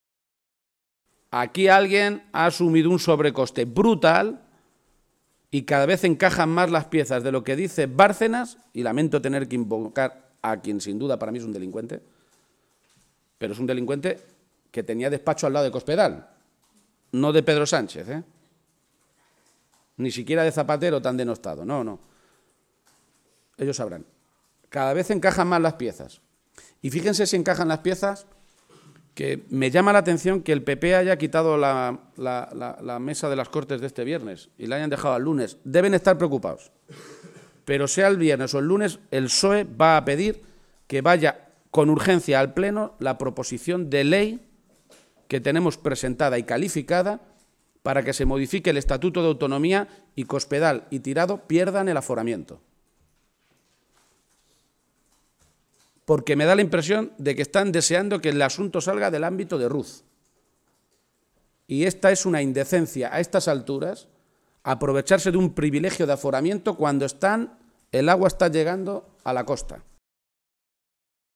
García-Page ha hecho este anuncio en la misma comparecencia ante los medios de comunicación en la que ha comprometido un programa para garantizar un empleo y unos ingresos al nivel del salario mínimo para todos los parados de larga duración mayores de 55 años.